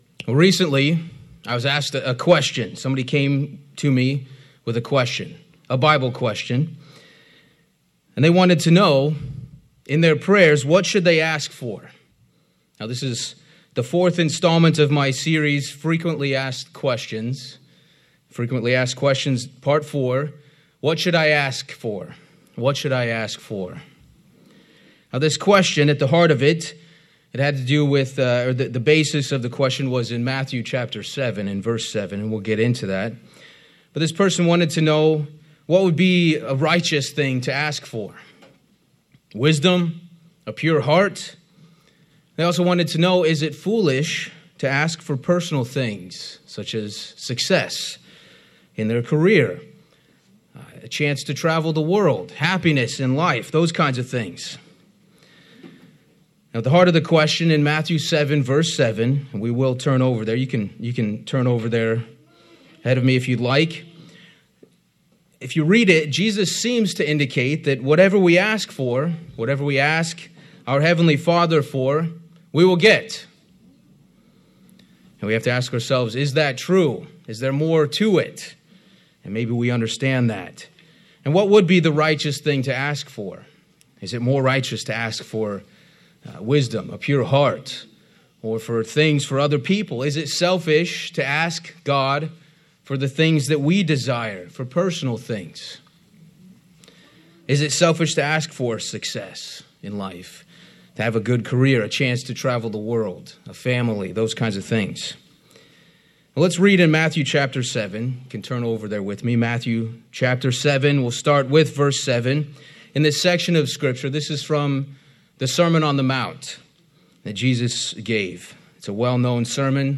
Is it selfish to ask God for personal things? This sermon begins to explore the concept of where our focus is when we pray.